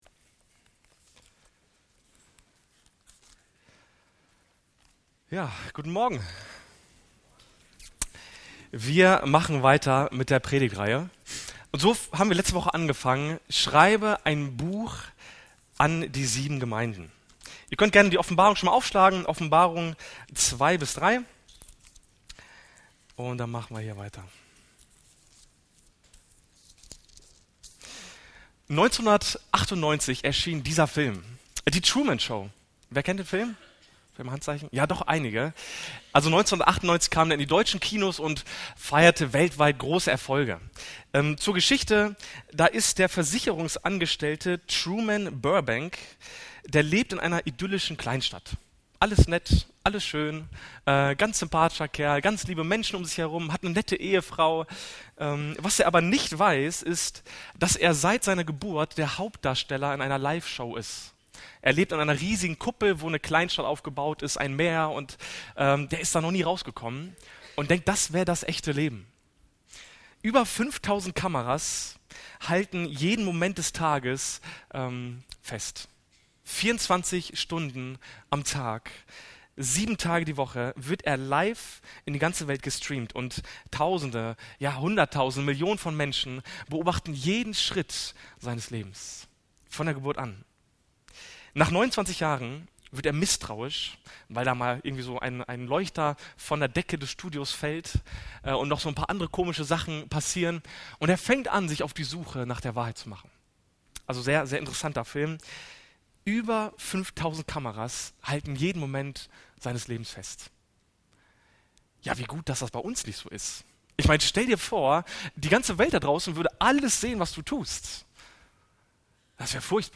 Predigten – Freie Christen-Gemeinde Lichtenau